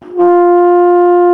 TENORHRN F 2.wav